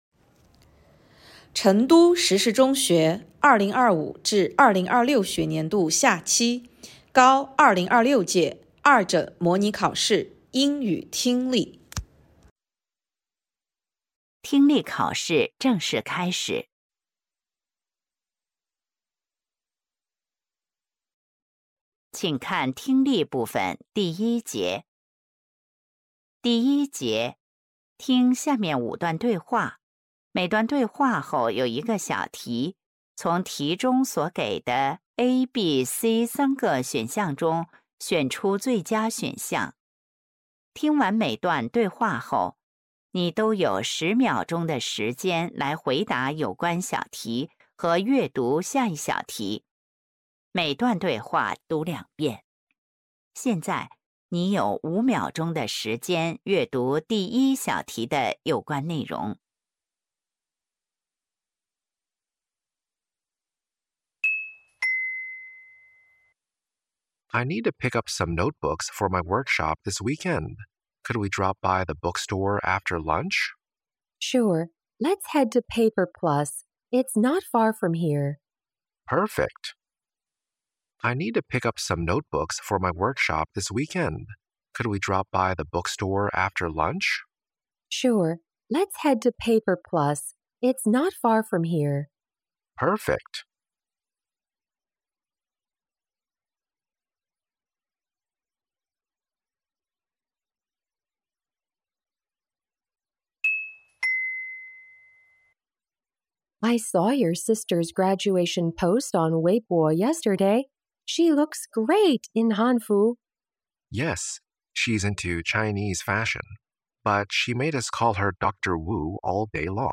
成都石室中学2026届高三二诊模拟考试英语听力.mp3